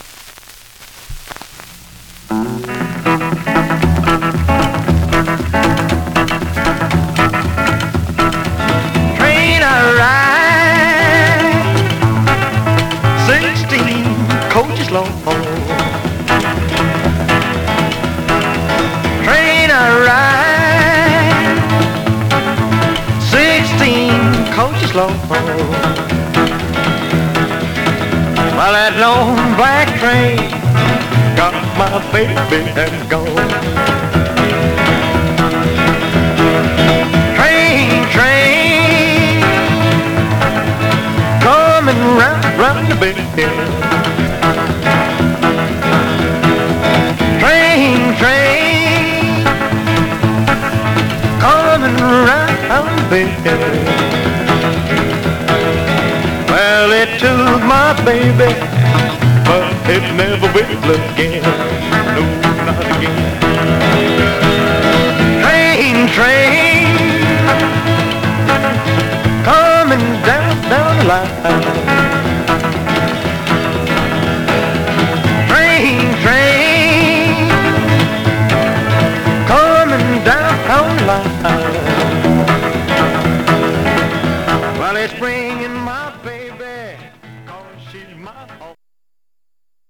Surface noise/wear Stereo/mono Mono
Rockabilly